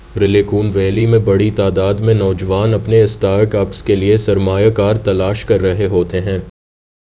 deepfake_detection_dataset_urdu / Spoofed_TTS /Speaker_11 /129.wav